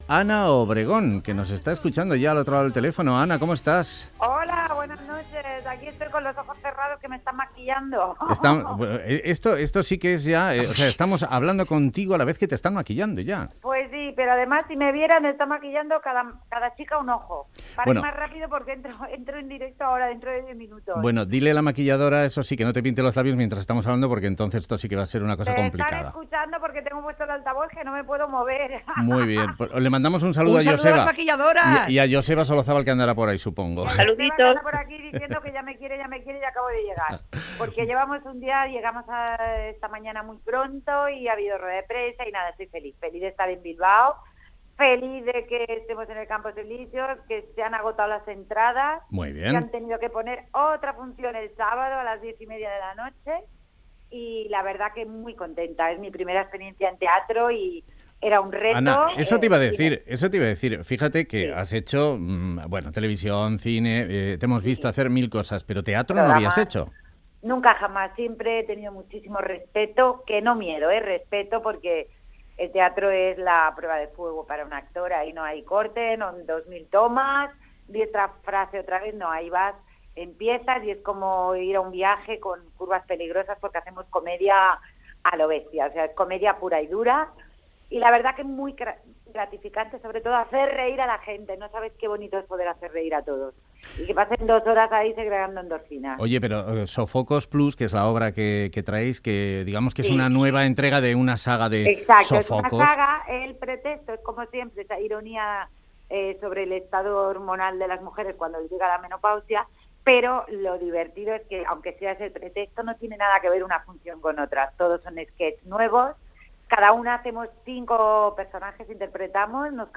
Radio Euskadi GRAFFITI